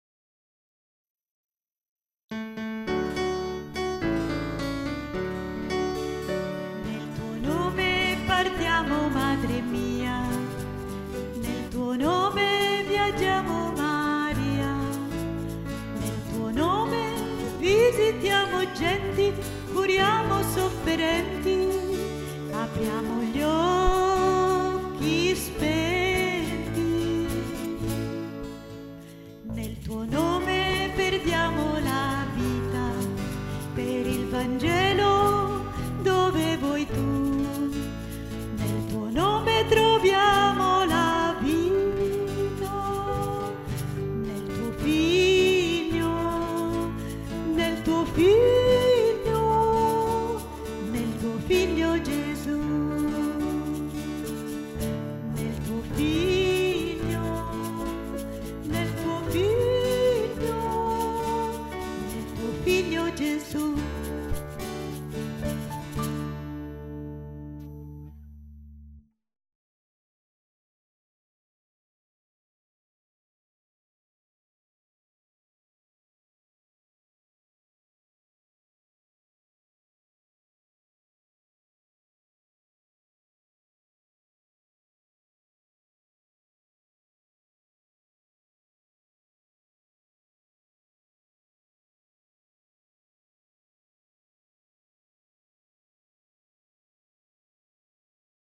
n. 24 preghiera mp3 – Missione canto mp3